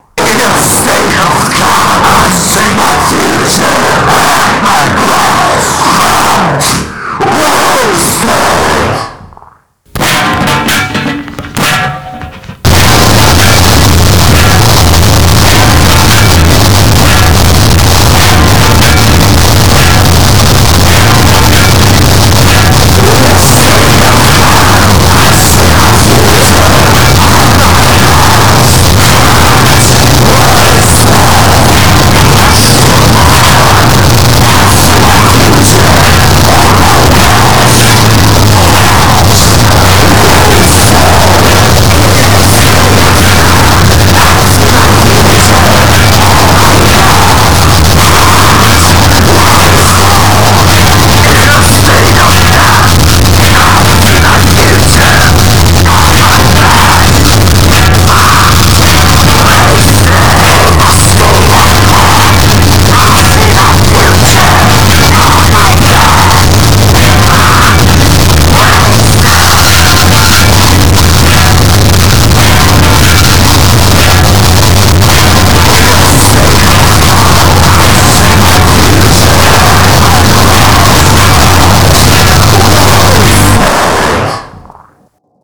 fuck you Harsh Noise and Power Electronics.
a brief blast of vocal anxiety and harshness